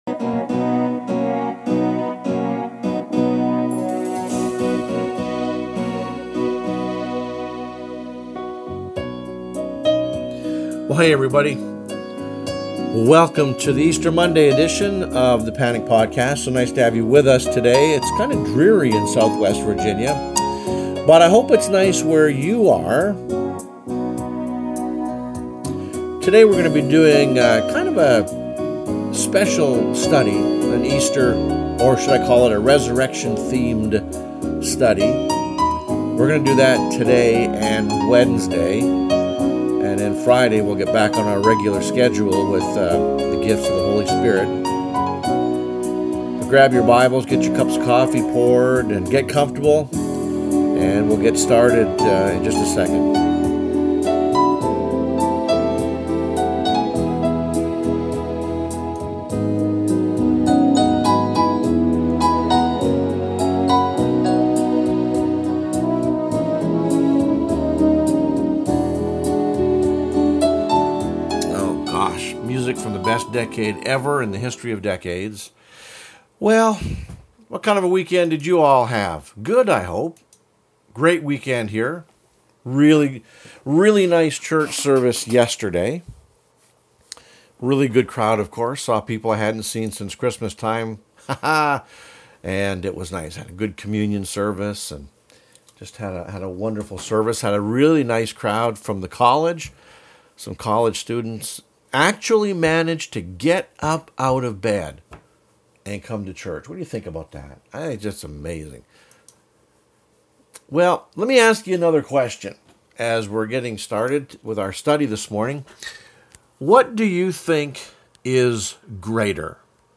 The Sunday Sermon – Trust God With Yourself